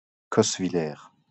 Cosswiller (French pronunciation: [kɔsvilɛʁ]